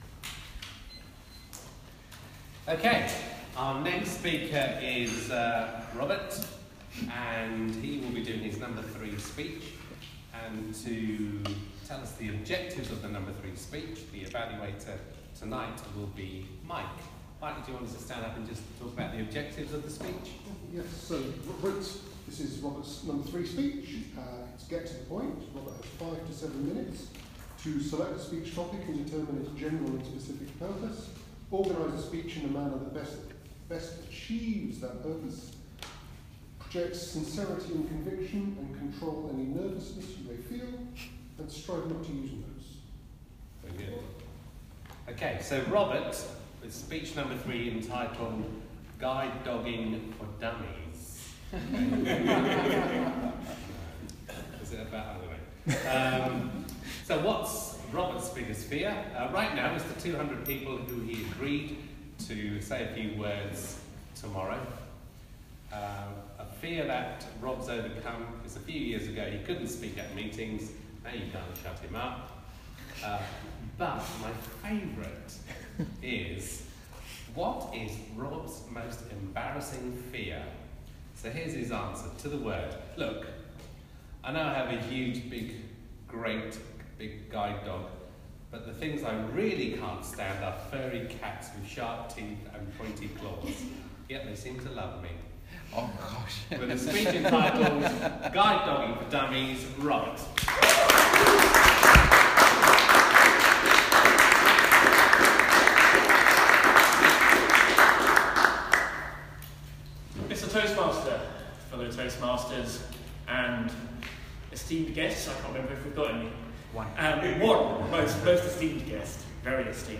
Toastmasters Speech Three: Guide Dogging for Dummies
The introduction was far too long and I could have done with planning the ending a little better.